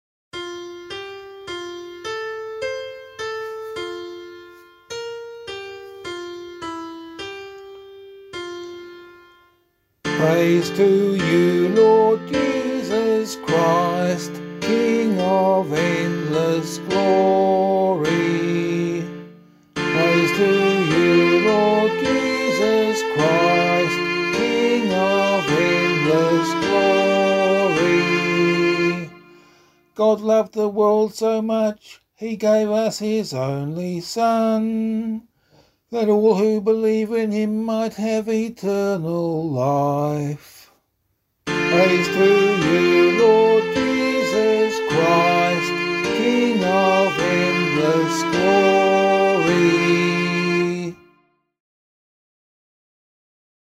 Gospelcclamation for Australian Catholic liturgy.